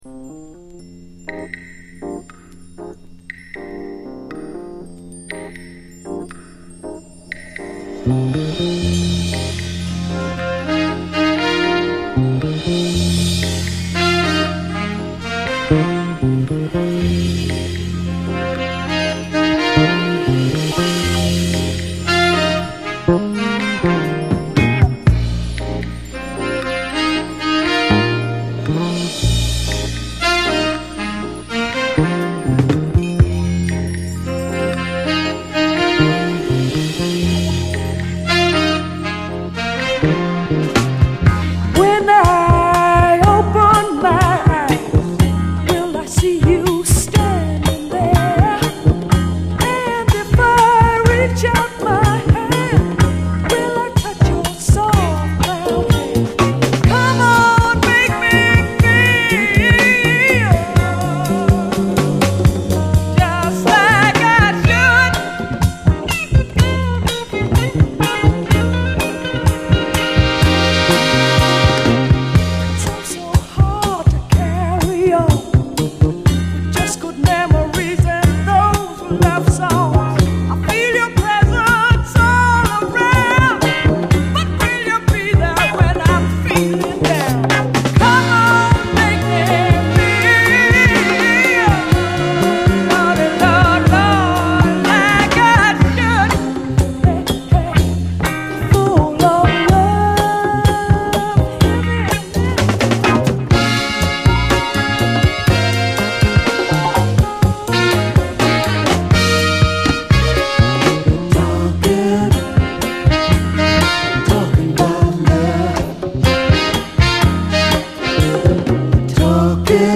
SOUL, 70's～ SOUL
静かなイントロからドラマティックなヤバいムード全開、痺れるほどカッコいい一曲！